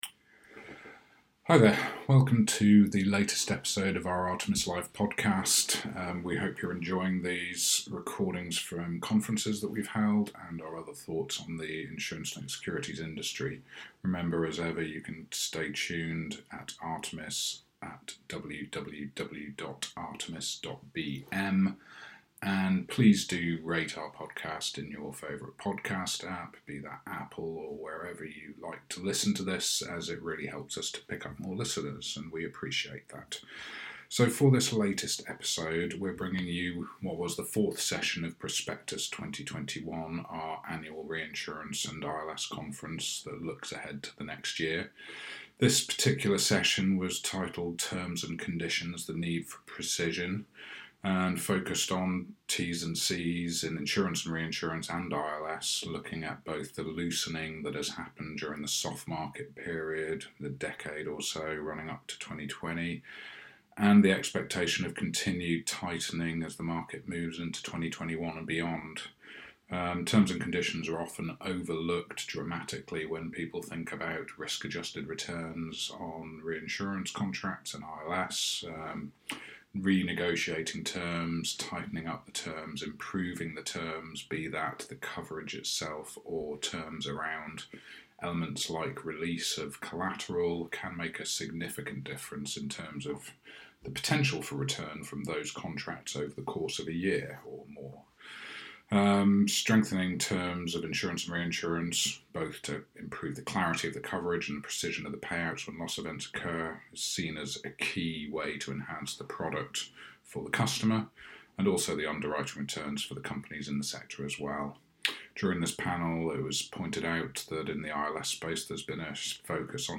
This was the fourth session of Prospectus 2021, a new annual reinsurance and insurance-linked securities (ILS) conference brought to you by Artemis in collaboration with sister title Reinsurance News in November 2020.